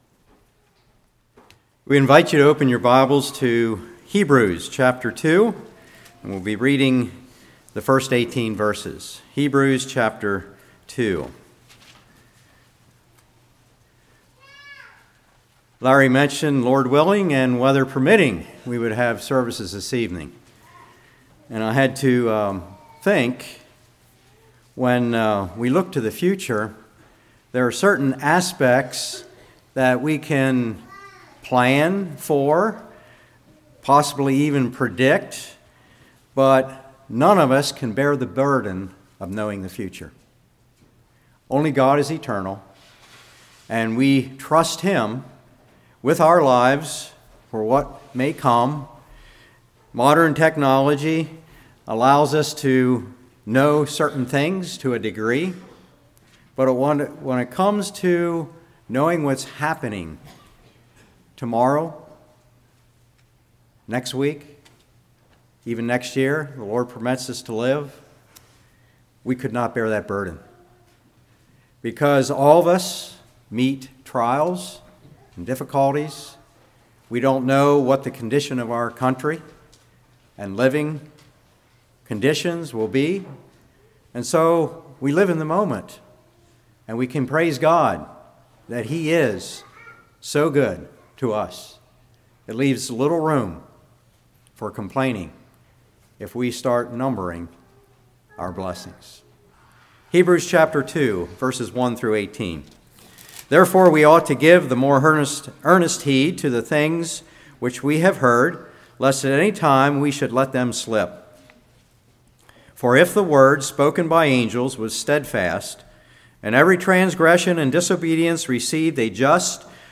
Hebrews 2:1-18 Service Type: Morning Beginning of kingdom Jesus’ primary purpose for coming.